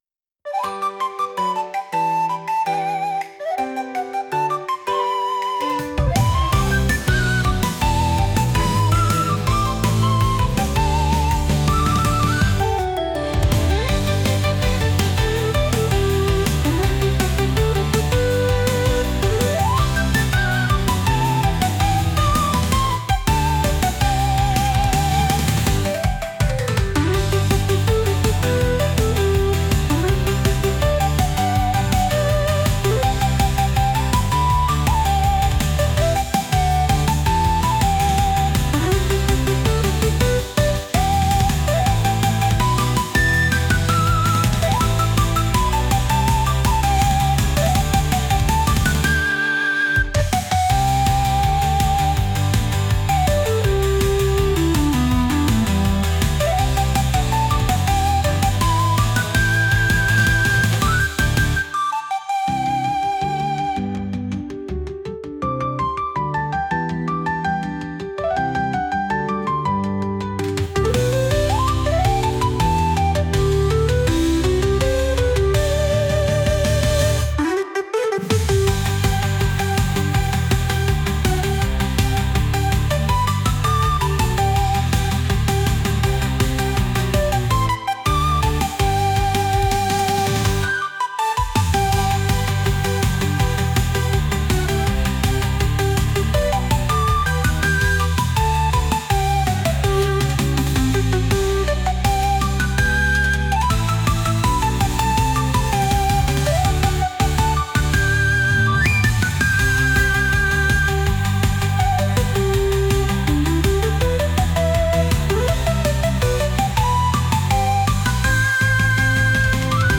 元気が出そうなリコーダー音楽です。